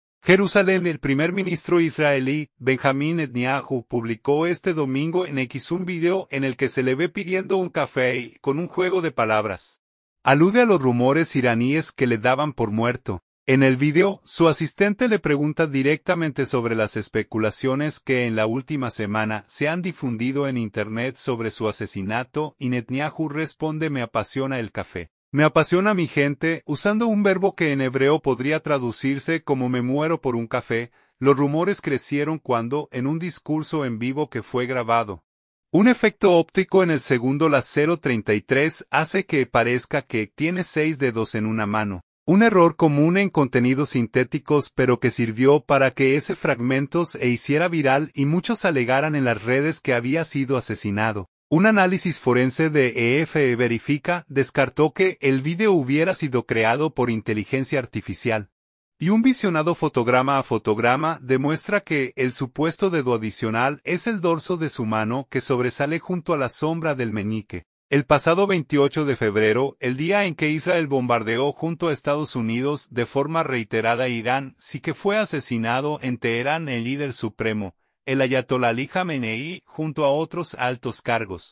En el vídeo, su asistente le pregunta directamente sobre las especulaciones que en la última semana se han difundido en internet sobre su asesinato, y Netanyahu responde «me apasiona el café (…) Me apasiona mi gente», usando un verbo que en hebreo podría traducirse como «me muero por un café».